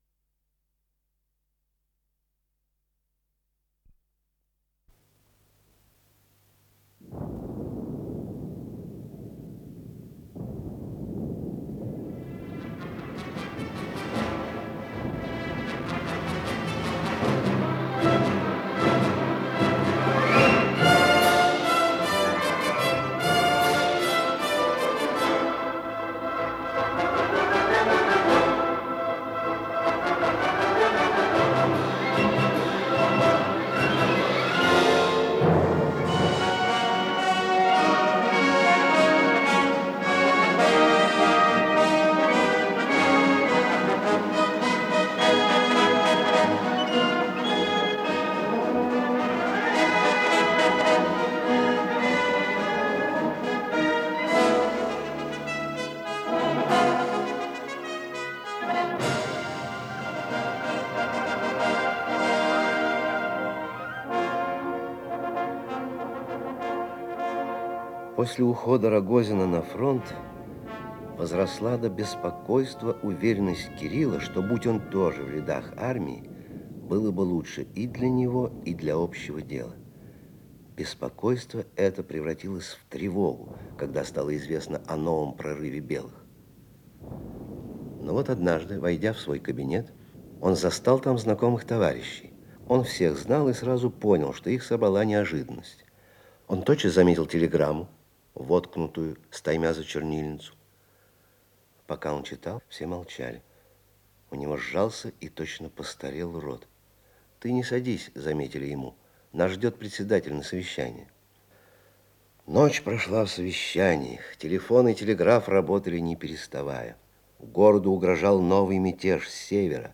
Исполнитель: Артисты московских театров
Инсценированные страницы романа